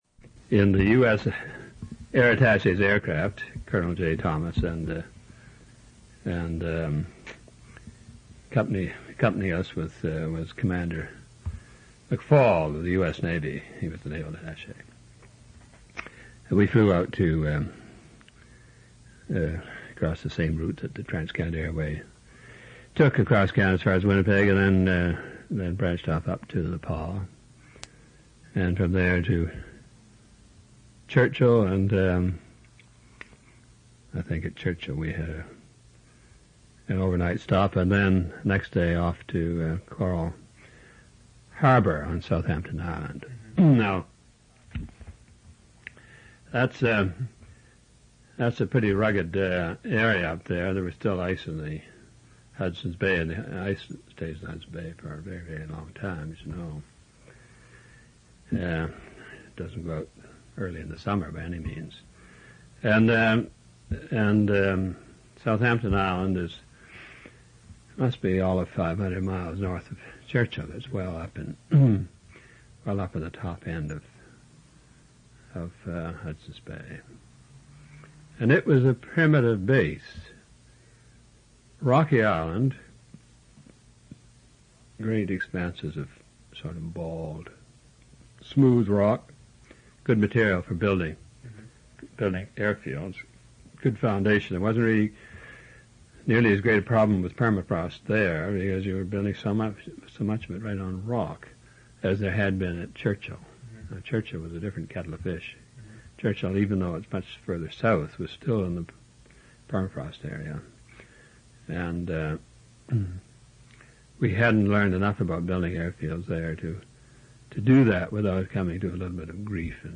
In Collection: Canadian Military Oral Histories